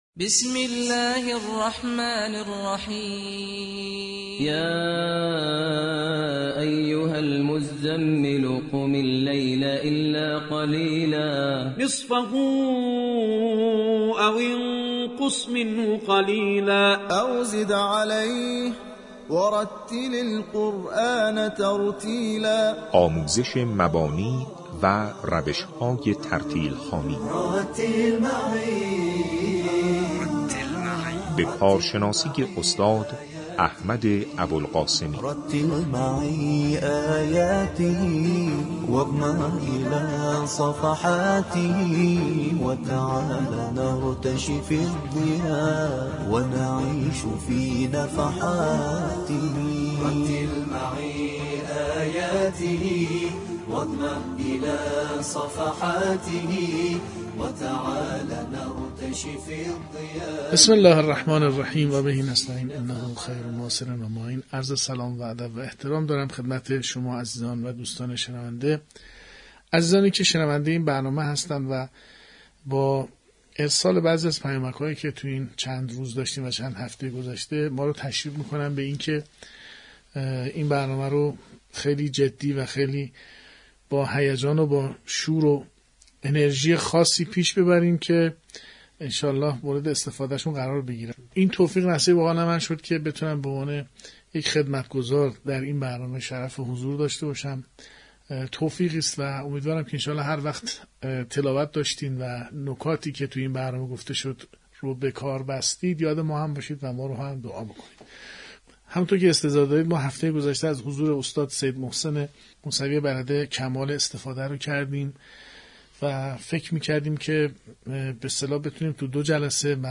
به گزارش خبرنگار فرهنگی باشگاه خبرنگاران تسنیم «پویا» رادیو قرآن شب گذشته (شنبه 6 آذرماه 95) ساعت 20 چهارمین قسمت آموزش ترتیل قرآن کریم را منتشر کرد و همزمان فایل این برنامه در شبکه‌های اجتماعی نیز منتشر شد.